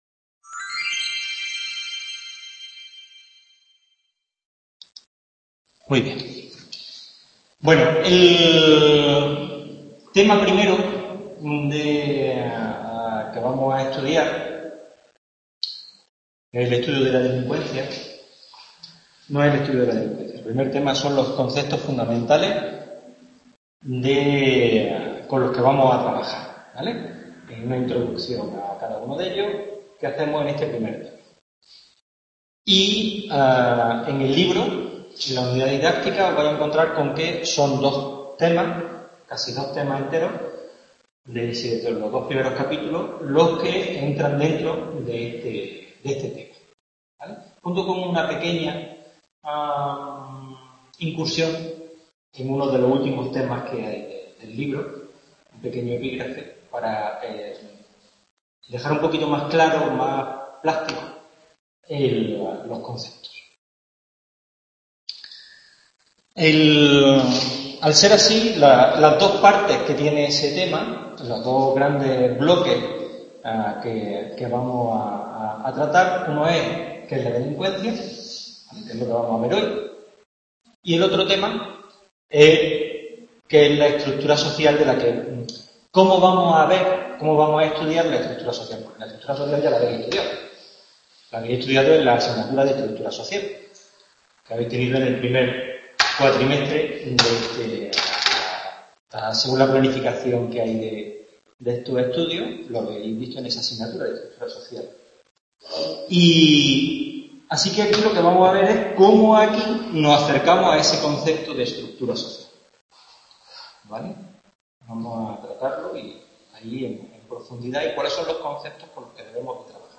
Tutoría en el Centro Asociado de Jacinto Verdaguer de la asignatura Delincuencia y Vulnerabilidad. Exposición del Capítulo 1 del libro Lugares Vulnerables.